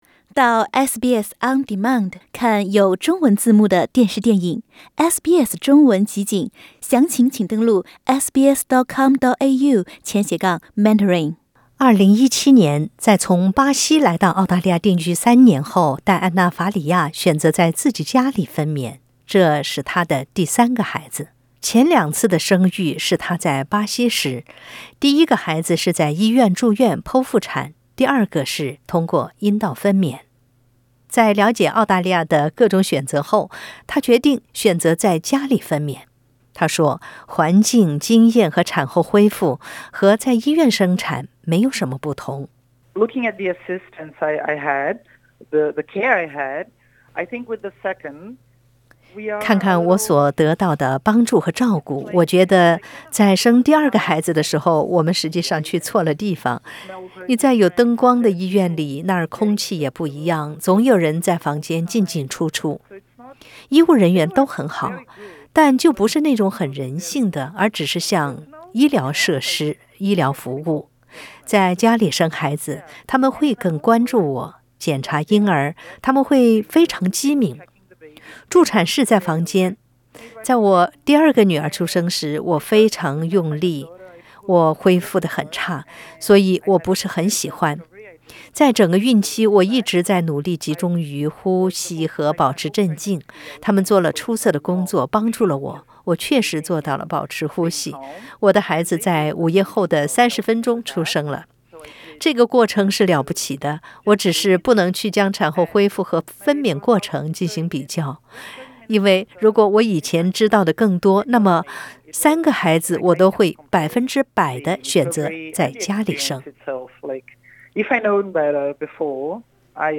SBS Mandarin